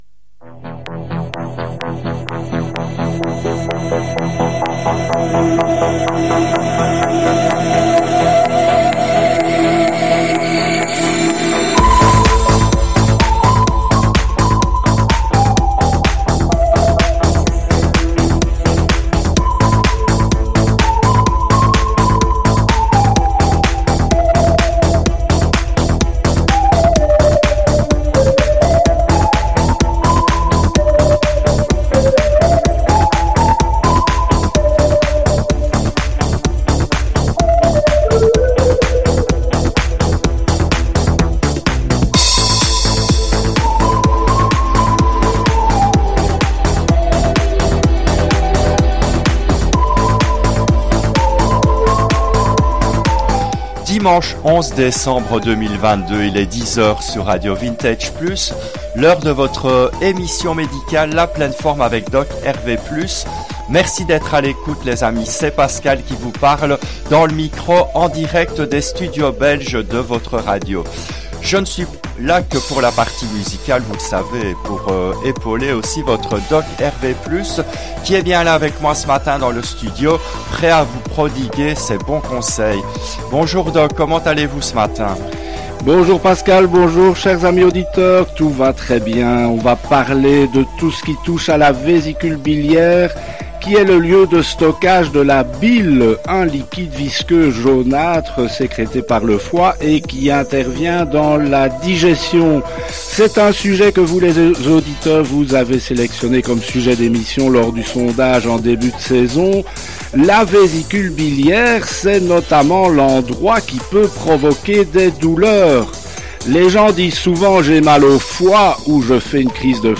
Cette émission a été diffusée en direct le dimanche 11 décembre 2022 à 10 heures depuis les studios belges de RV+.